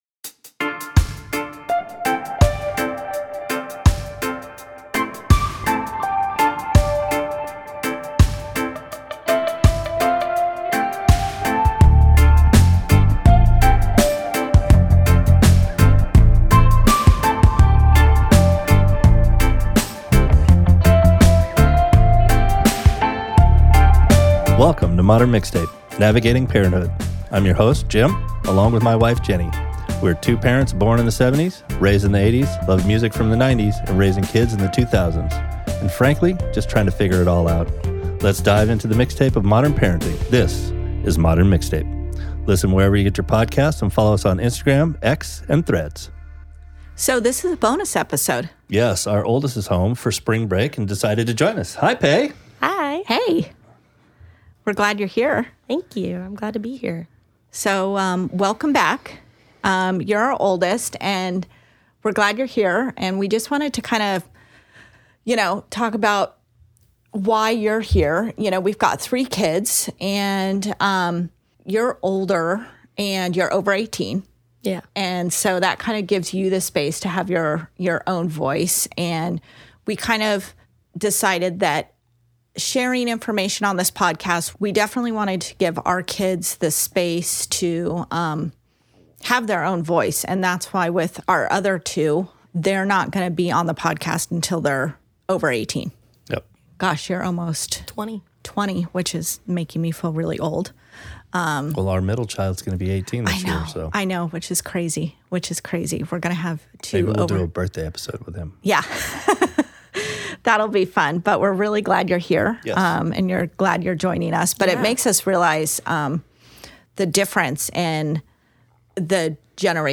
Talking life skills, transition to college and how different our generations are. Candid conversations about life skills, failure, and laughing along the way.